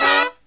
FALL HIT08-L.wav